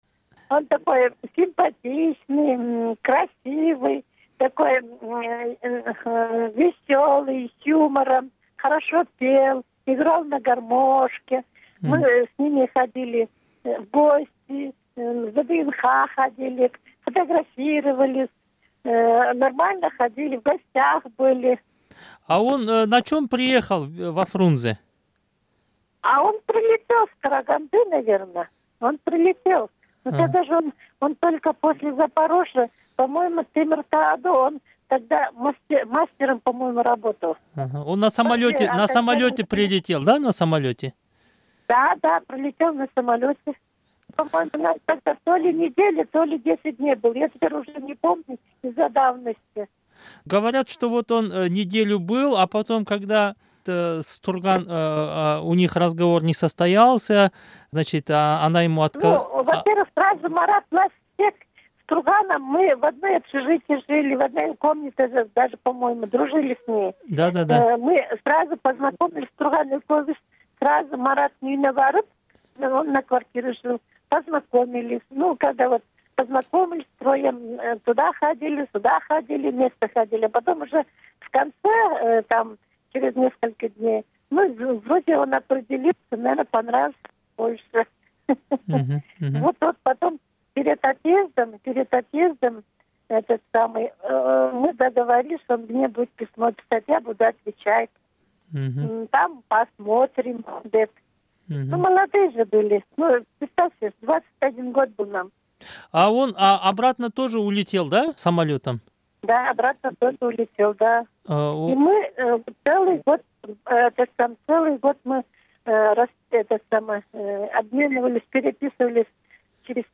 Фрагмент интервью